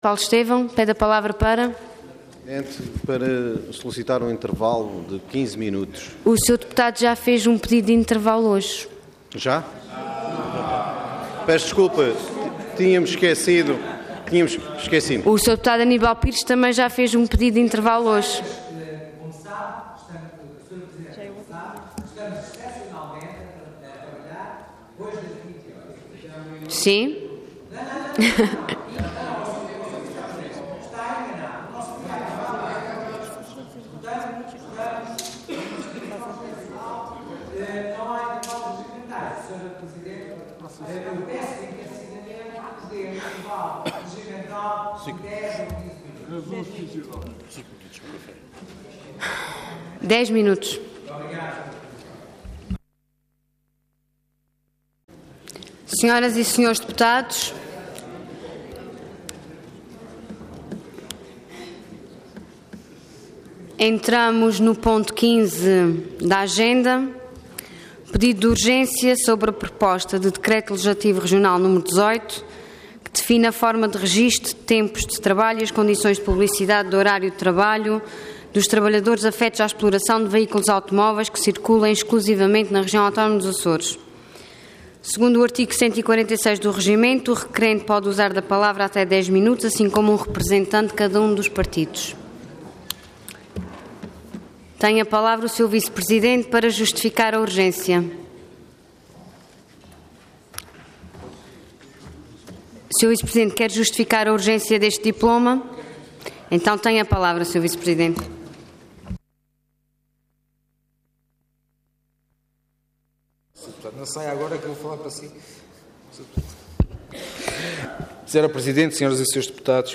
Assembleia Legislativa da Região Autónoma dos Açores
Intervenção
Luiz Fagundes Duarte
Secretário Regional da Educação, Ciência e Cultura